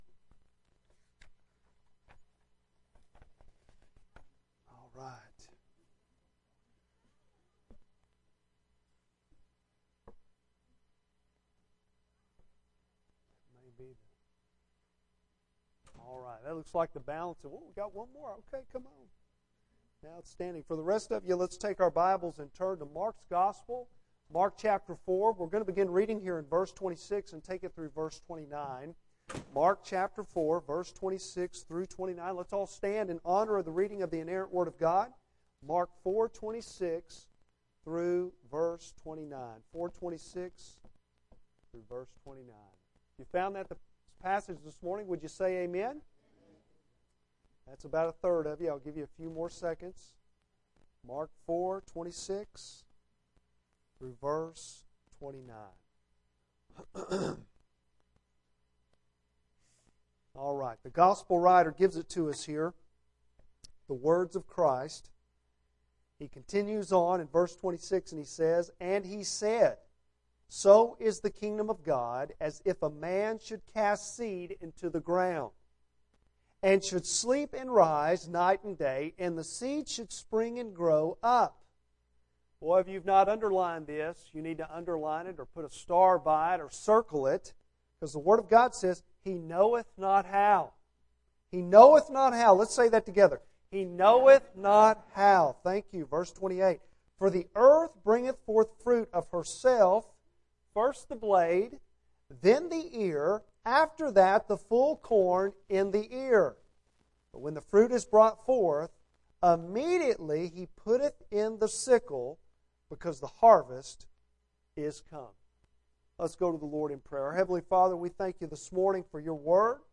Bible Text: Mark 4:26-29 | Preacher